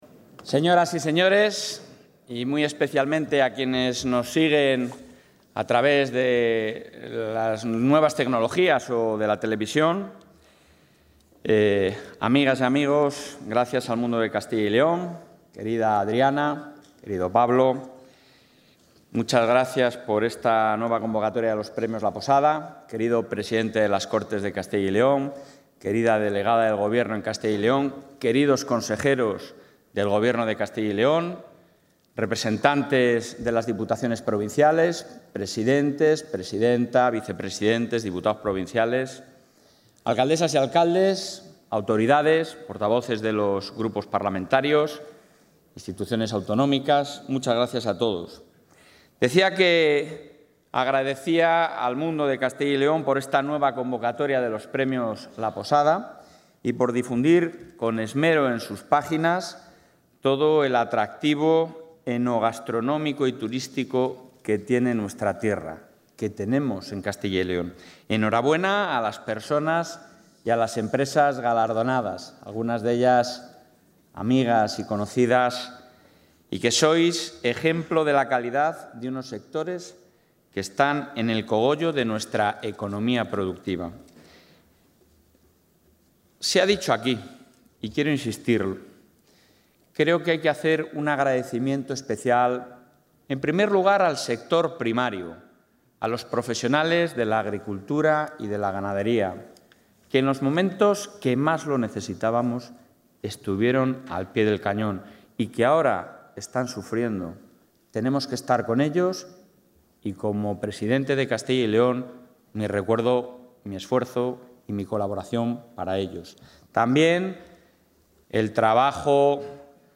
Intervención del presidente de la Junta.
El presidente de la Junta subraya, en la entrega de los Premios La Posada, el apoyo al sector agropecuario, turístico y de la restauración